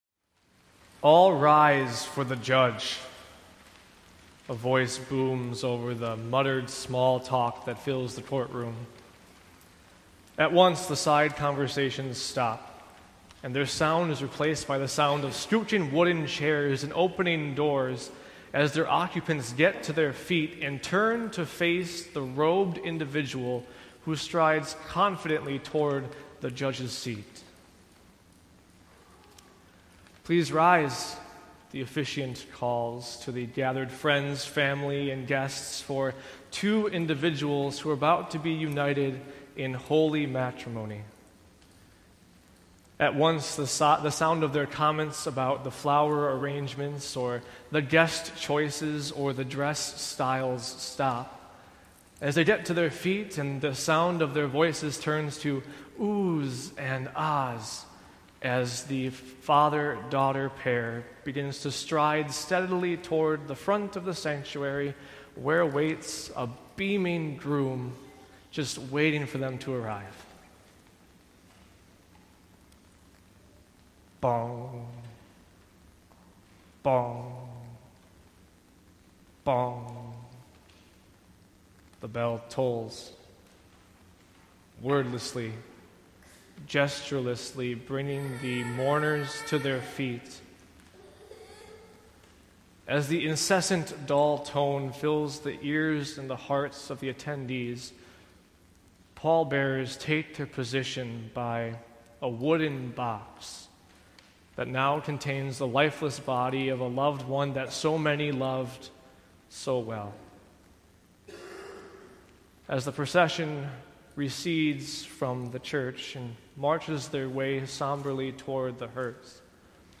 The theme for today’s service: Live with Your Eyes on the Skies.
Gospel and Sermon Text: Mark 13:26-37.